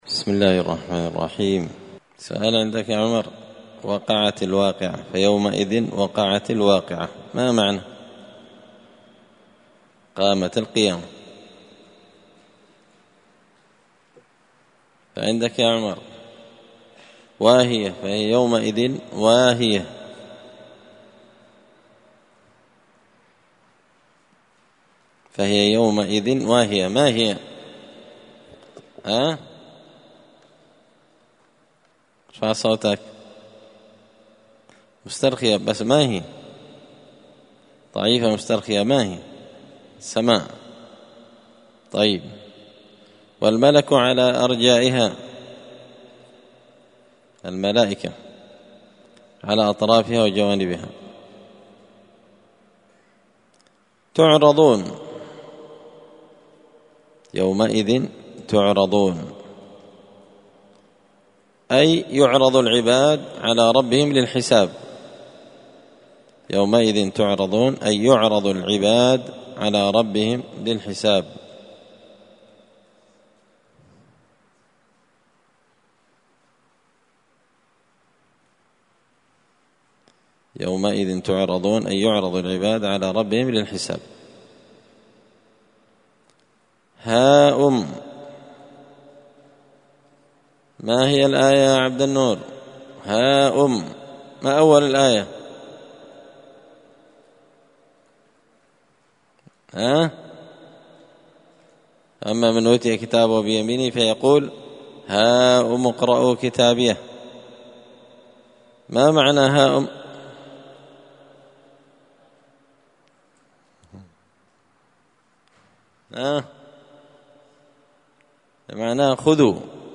71الدرس-الحادي-والسبعون-من-كتاب-زبدة-الأقوال-في-غريب-كلام-المتعال.mp3